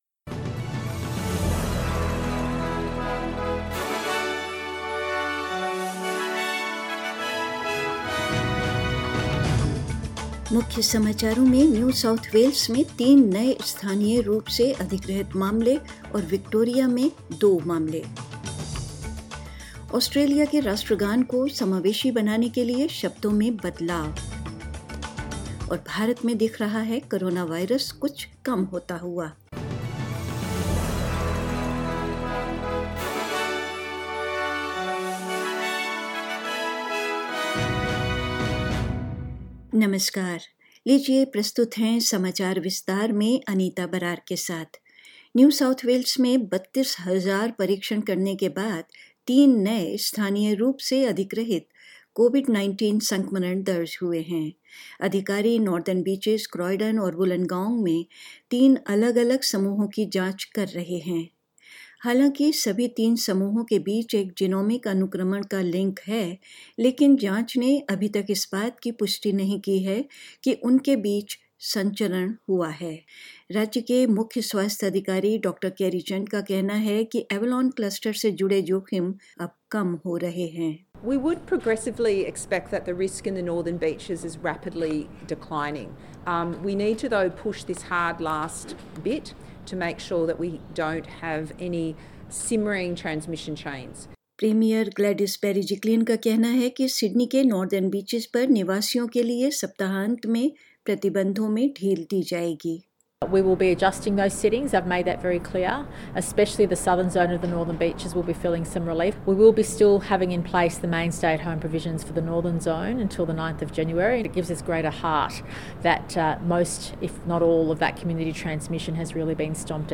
News in Hindi 1st January 2021